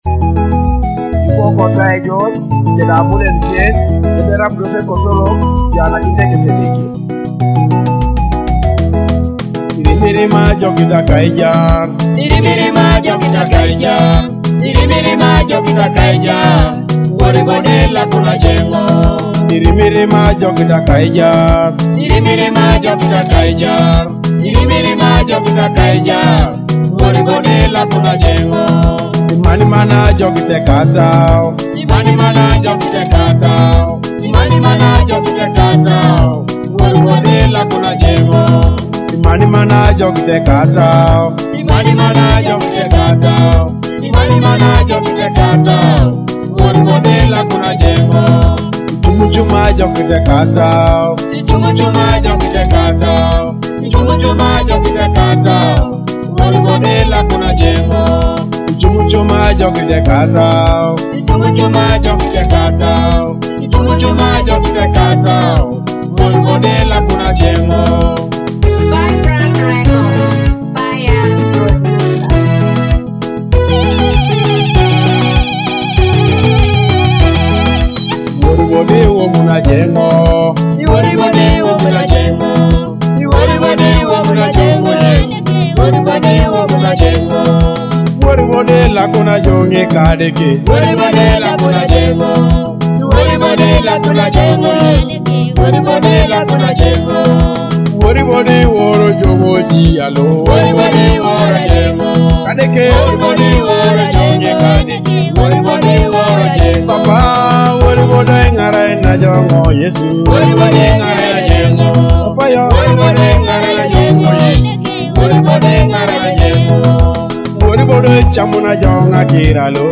gospel hit